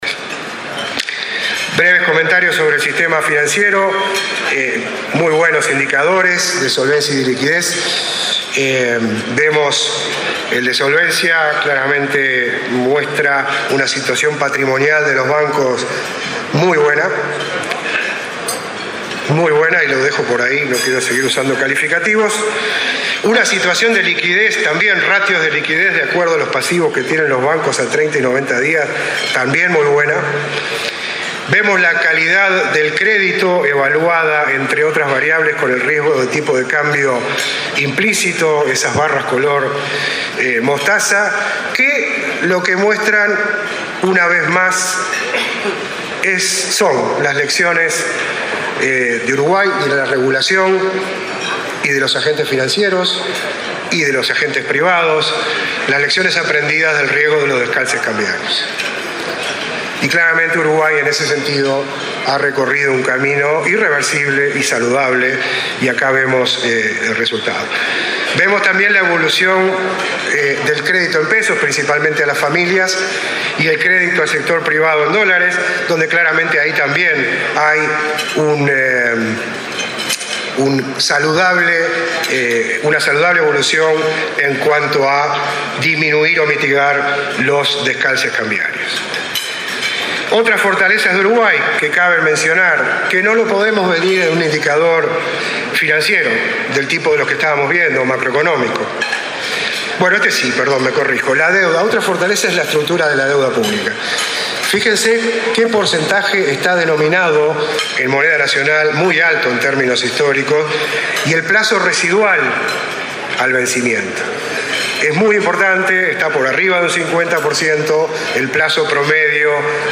El presidente del Banco Central del Uruguay, Alberto Graña, destacó este miércoles en el almuerzo de ADM, las fortalezas de Uruguay en materia macroeconómica, financiera e institucional que repercuten en una muy baja prima por riesgo país, teniendo en cuenta el contexto regional, y en su calificación de riesgo crediticio.